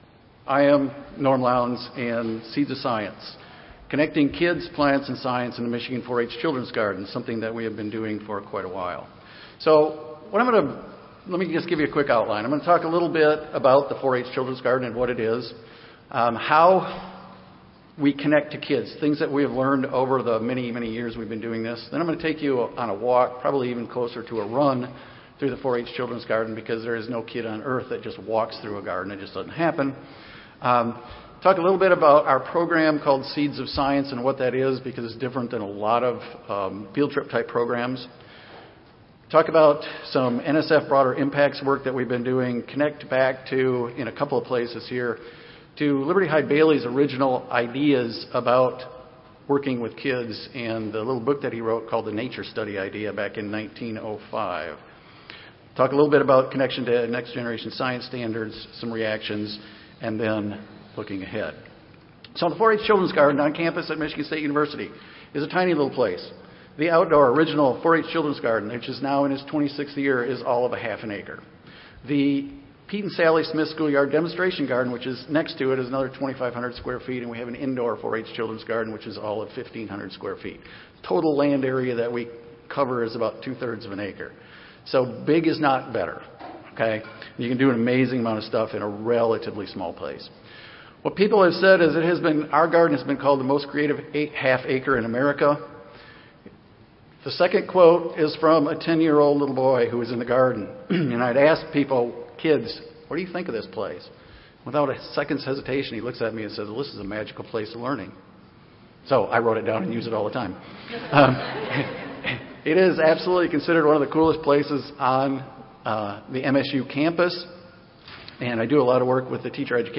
Plenary Session & ASHS Awards Ceremony
Recorded Presentation